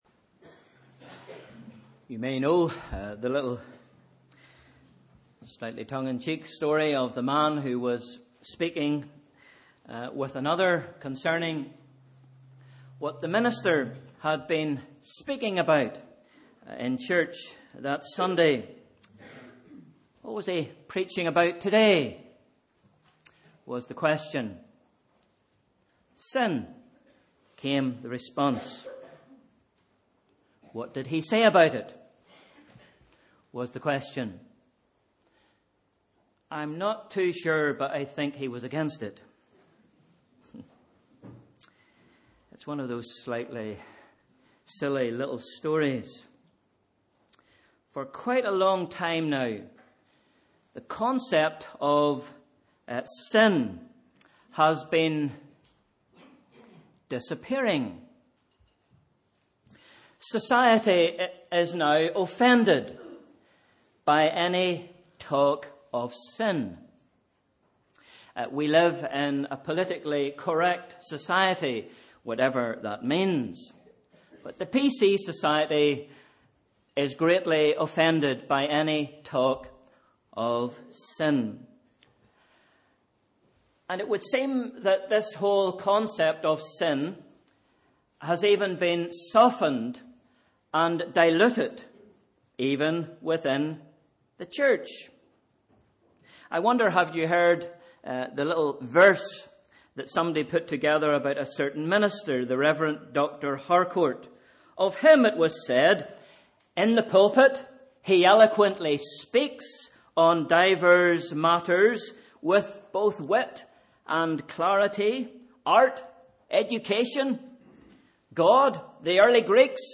Sunday 17th January – Morning Service